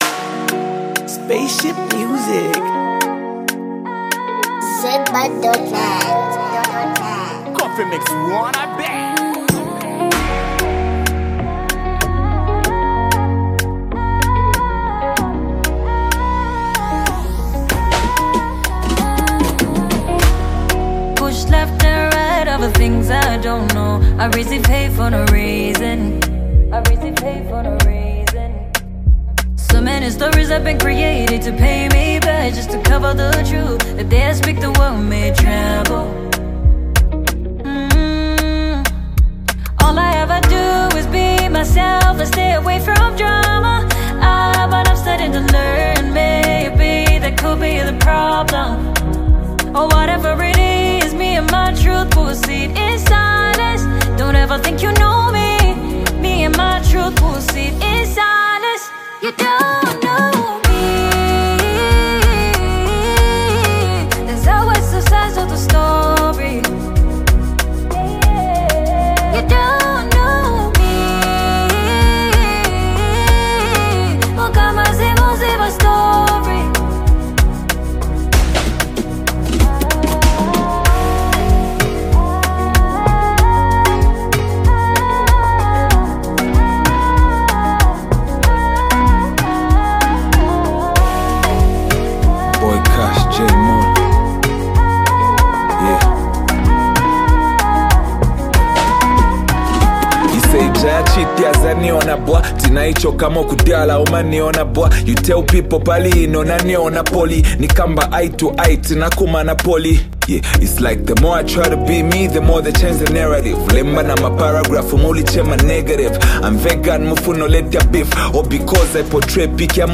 b>" blends traditional African rhythms with modern sounds.